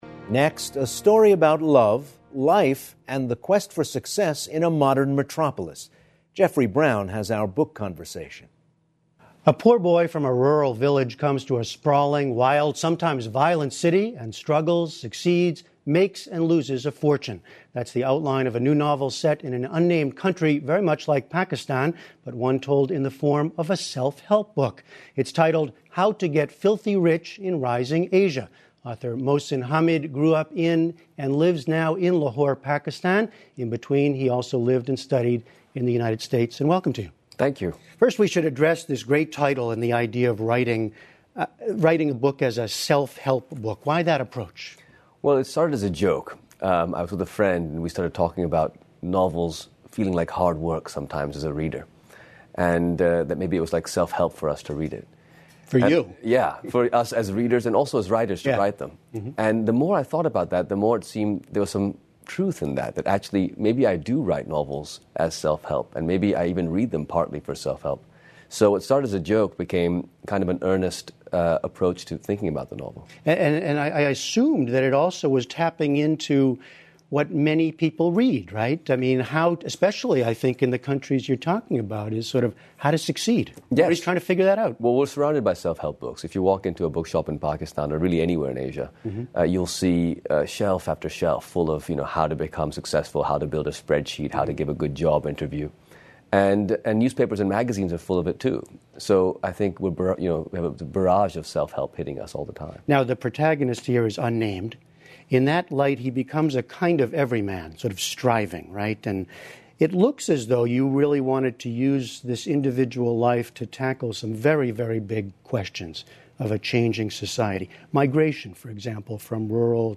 英语访谈节目:哈米德的新小说《如何在崛起的亚洲赚取不义之财》